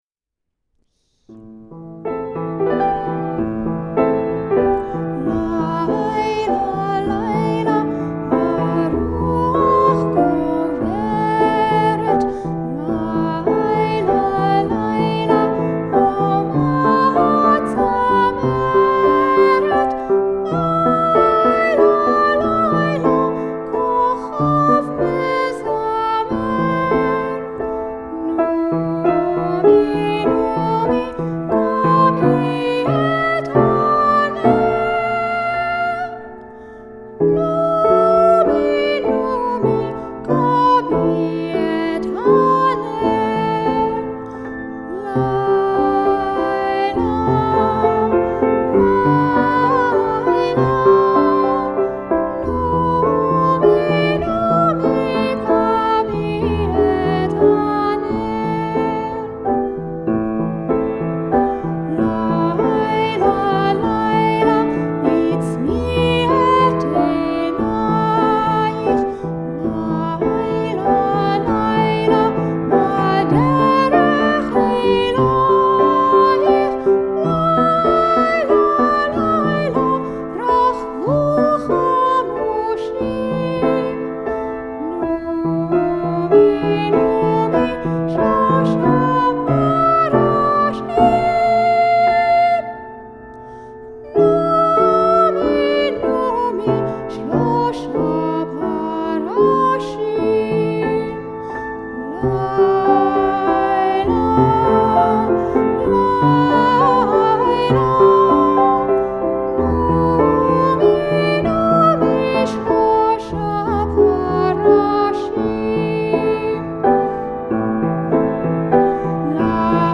an Israeli lullaby
piano (recorded live